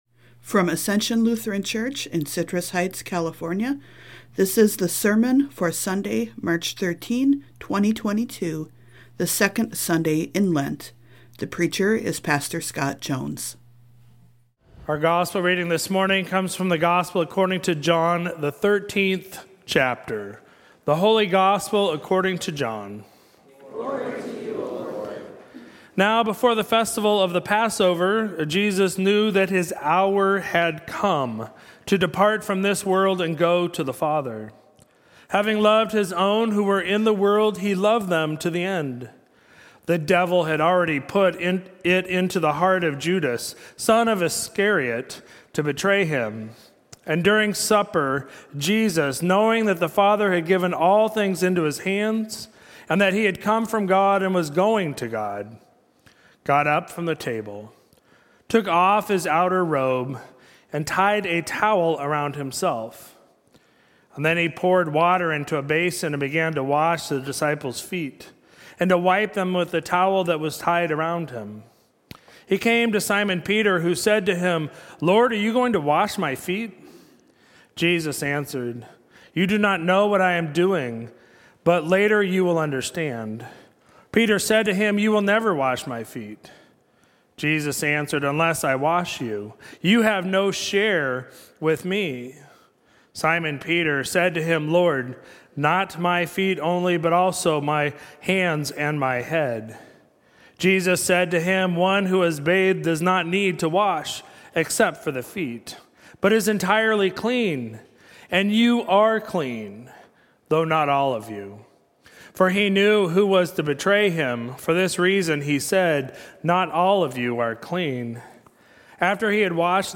Sermon for Sunday, March 13, 2022